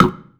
SNARE 119.wav